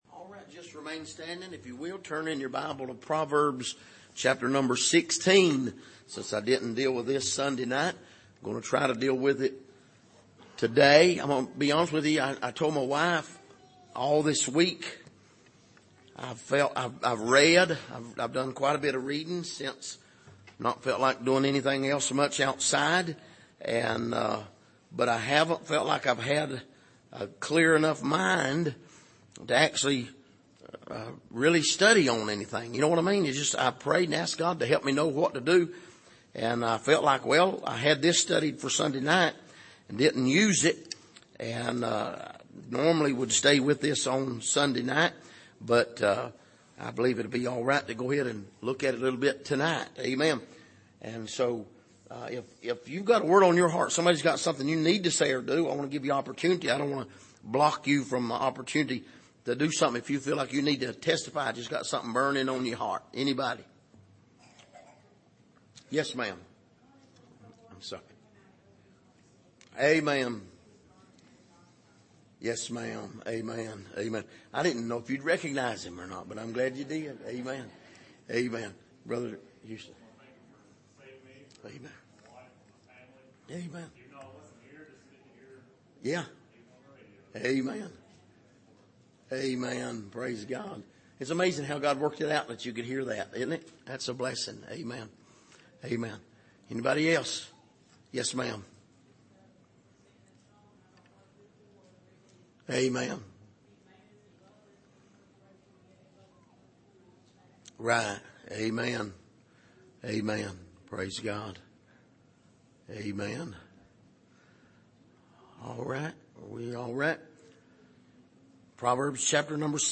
Passage: Proverbs 16:1-9 Service: Midweek